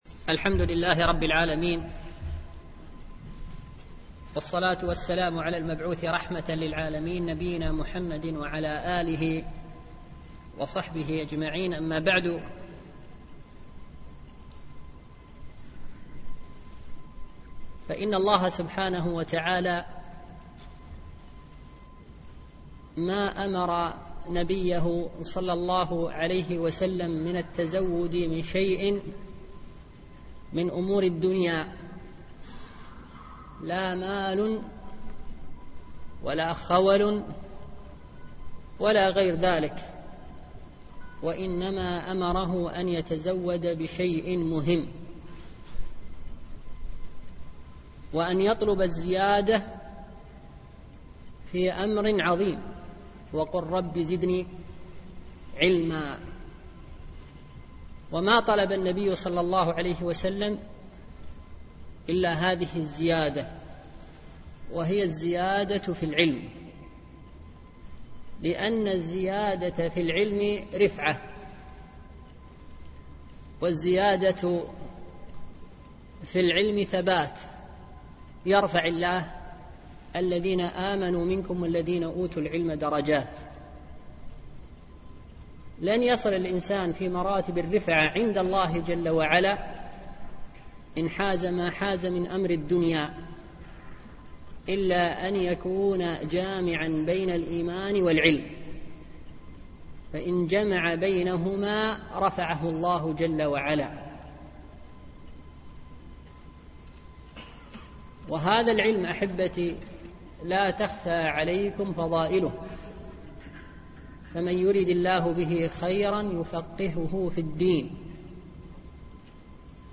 شرح عمدة الأحكام ـ الدرس الحادي والعشرون